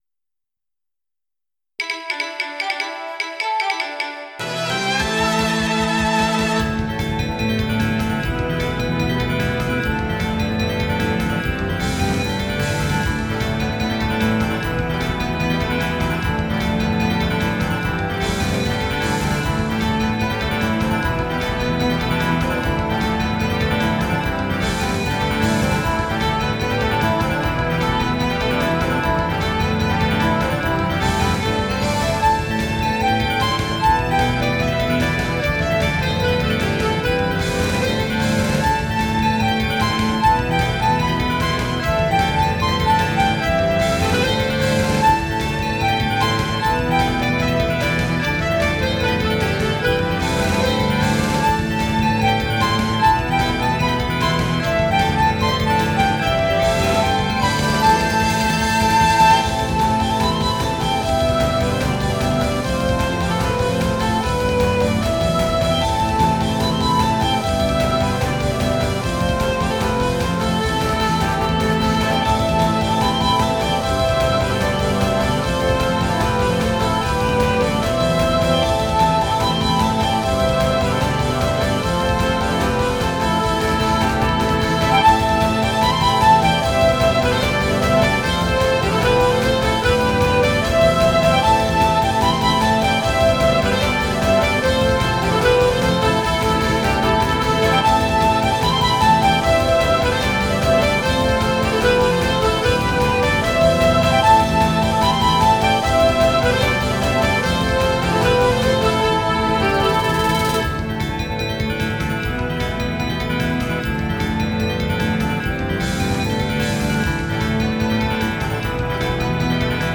(わちゃわちゃセッションver.)